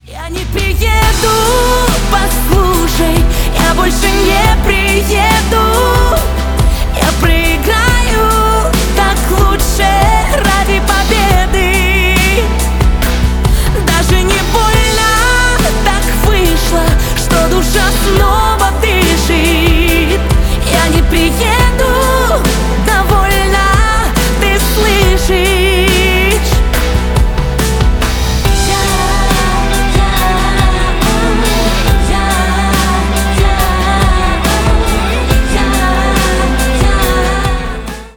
• Качество: 320, Stereo